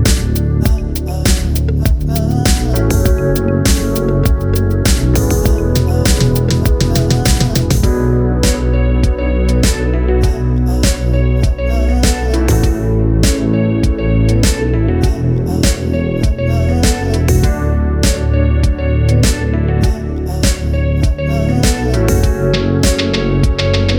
no Backing Vocals R'n'B / Hip Hop 3:44 Buy £1.50